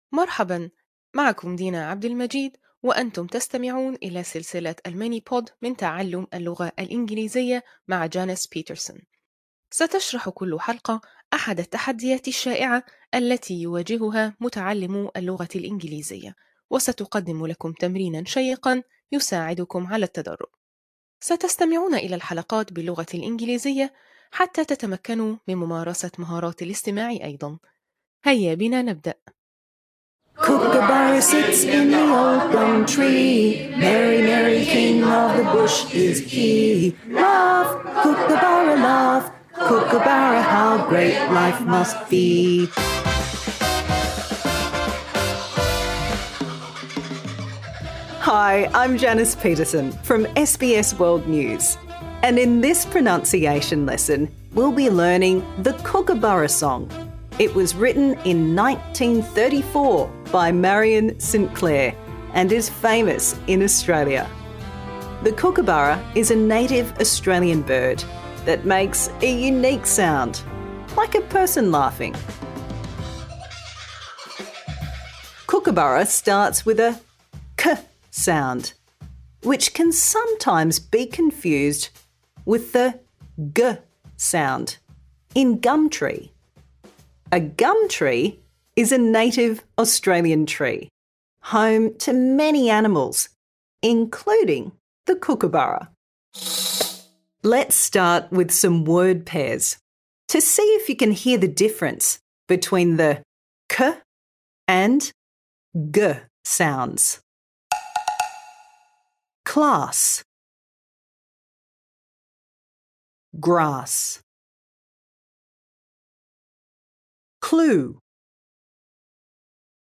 النقاط الرئيسية: أهداف الدرس: التمكن من نطق الأصوات /k/ and /g/. تأثير اللغة الأولى: غالبًا ما يتم الخلط بين /g/ و/k/ عند المتحدثين الفيتناميين والبرتغاليين.